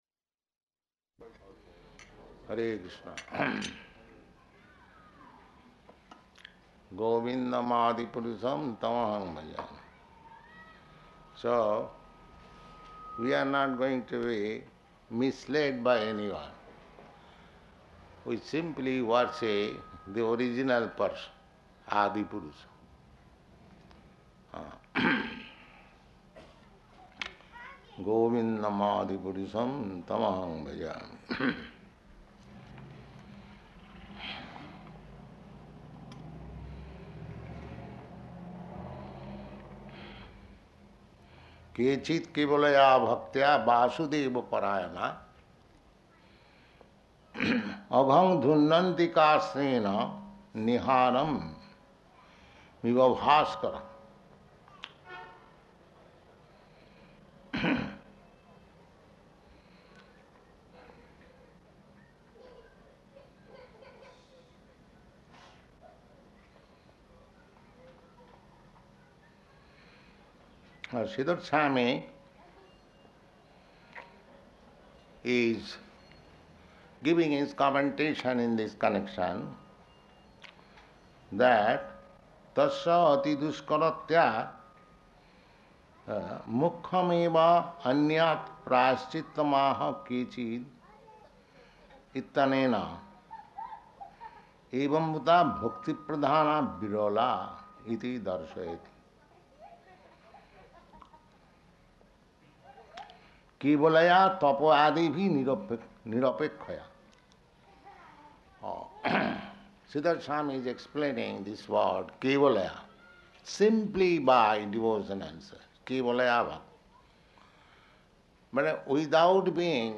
Śrīmad-Bhāgavatam 6.1.15 --:-- --:-- Type: Srimad-Bhagavatam Dated: August 1st 1971 Location: New York Audio file: 710801SB-NEW_YORK.mp3 Prabhupāda: Hare Kṛṣṇa.